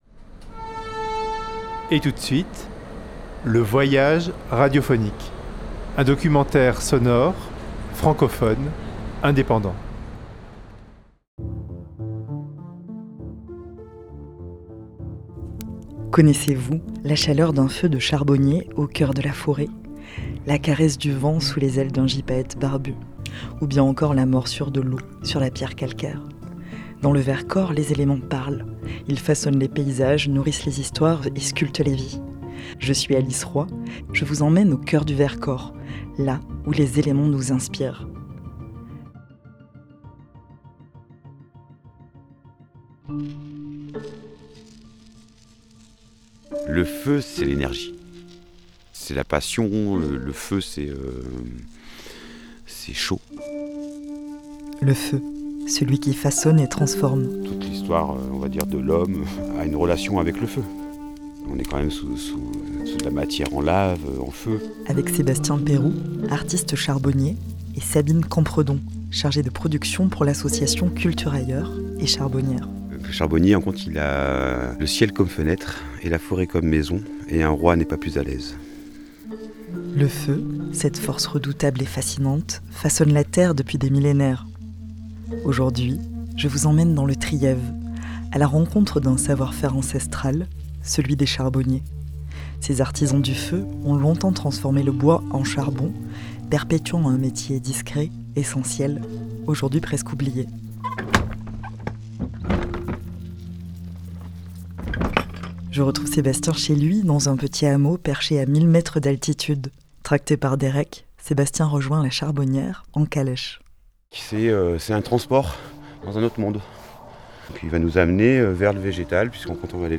Vercors, L'Inspiration des Éléments Un documentaire